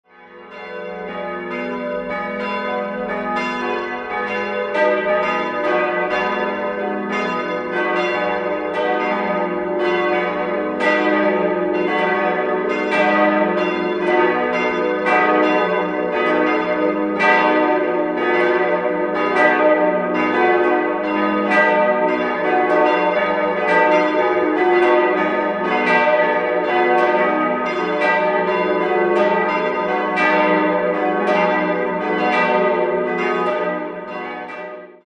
Beschreibung der Glocken
Idealquartett: dis'-fis'-gis'-h' Eine genaue Glockenbeschreibung folgt unten.